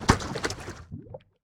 Minecraft Version Minecraft Version snapshot Latest Release | Latest Snapshot snapshot / assets / minecraft / sounds / mob / strider / step_lava4.ogg Compare With Compare With Latest Release | Latest Snapshot
step_lava4.ogg